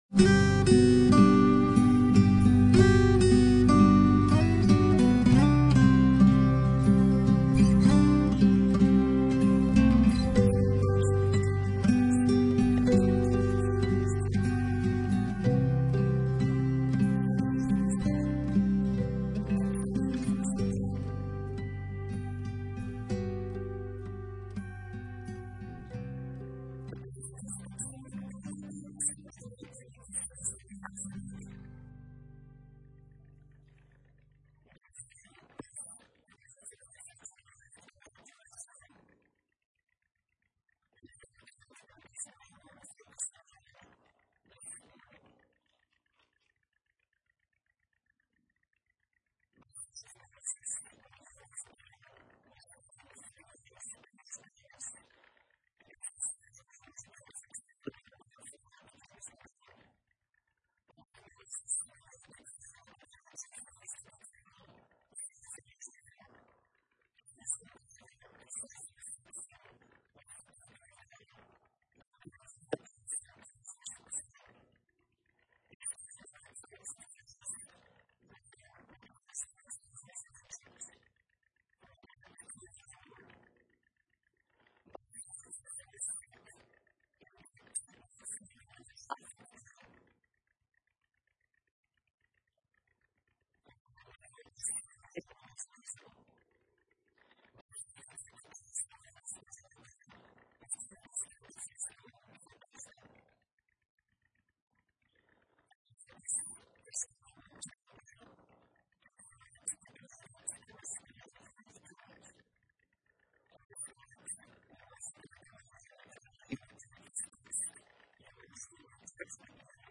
Christ Is Better Passage: Hebrews 11:23-28 Service Type: Sunday Morning Worship « By Faith